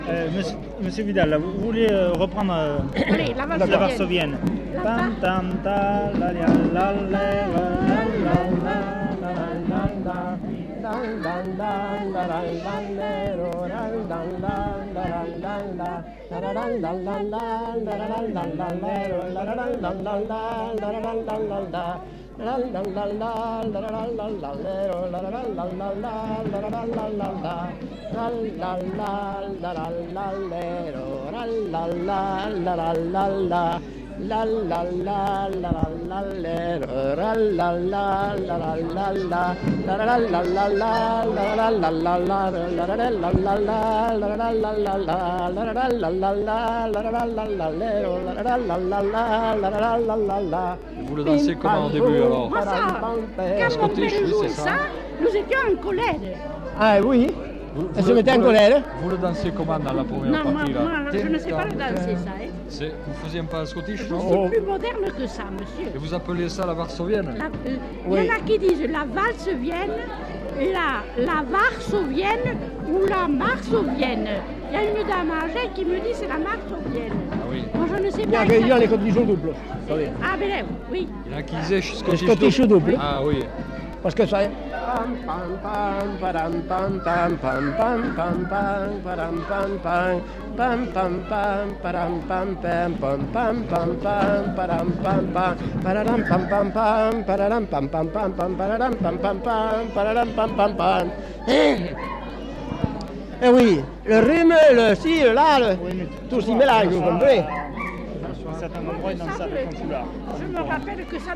Varsovienne Centre culturel.